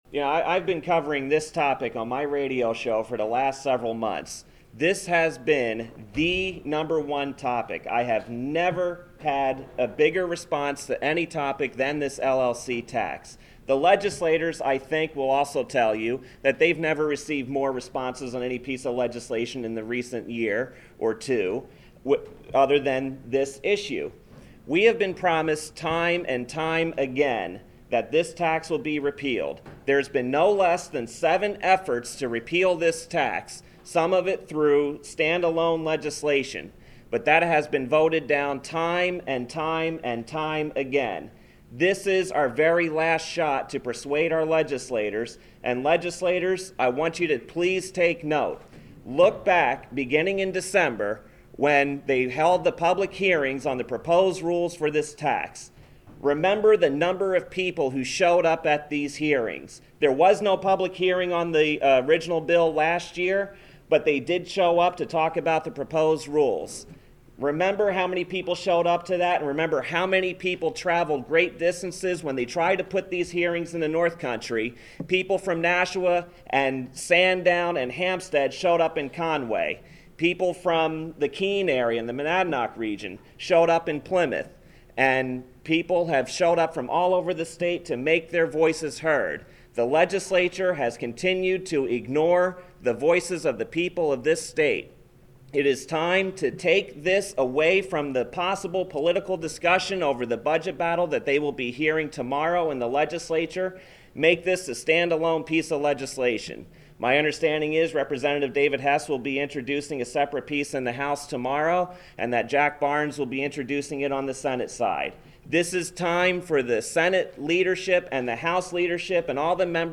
The following audio cuts are soundbytes from the 6/8/10 LLC Tax press conference at the State House and aired on the show: